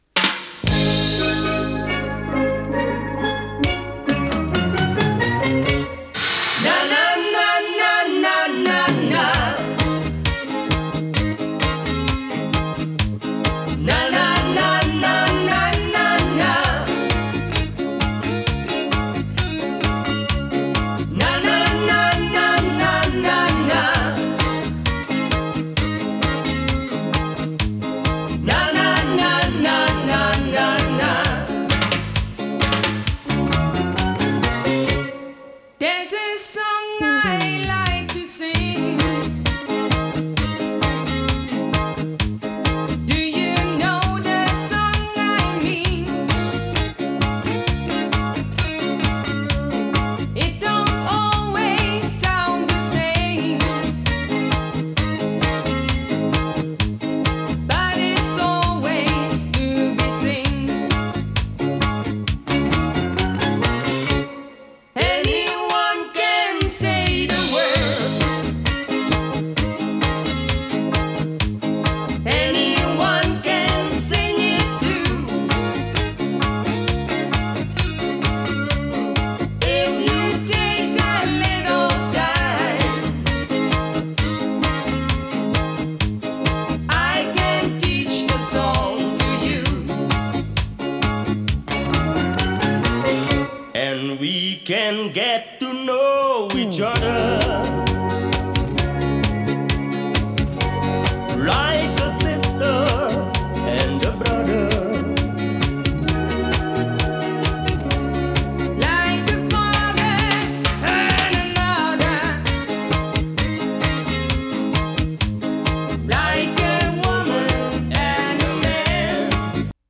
A面は、男女デュエットによるナナナ・コーラスのステッパーズ風ナンバー。